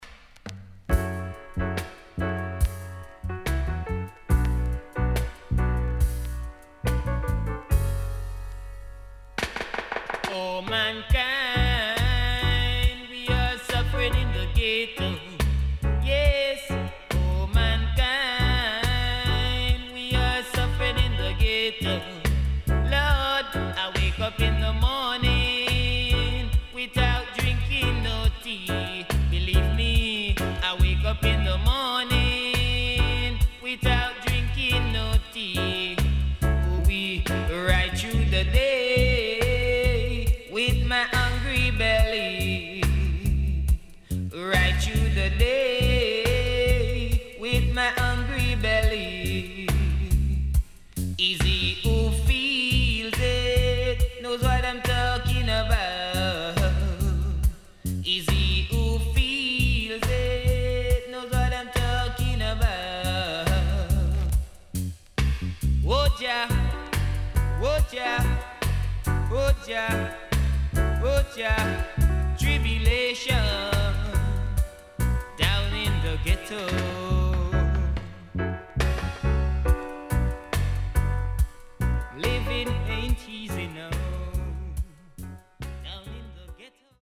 SIDE A:所々チリノイズがあり、少しプチノイズ入ります。